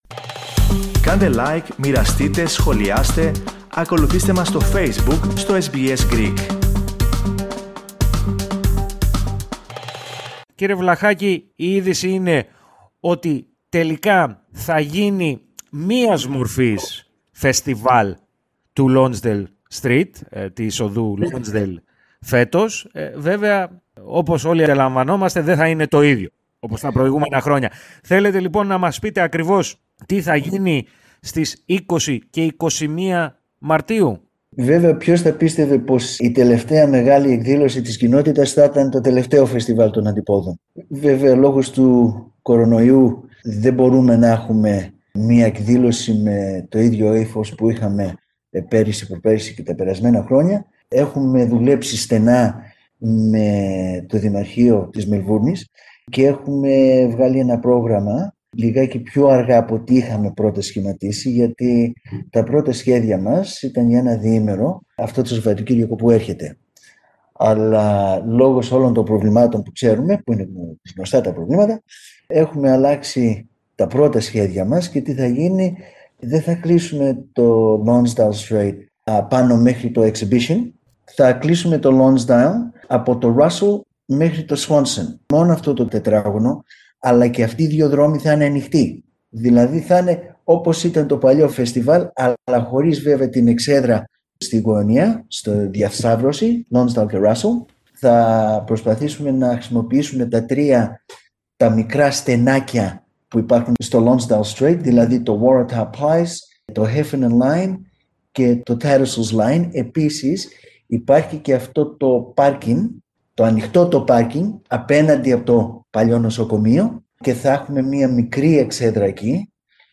μίλησε στο Ελληνικό Πρόγραμμα της ραδιοφωνίας SBS